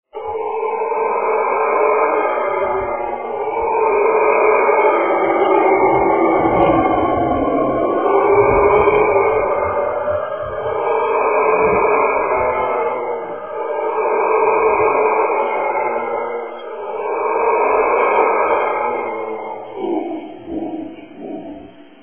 GELUID dat te vergelijken is met een soort storm. Het blijken brulapen te zijn!
howler.mp3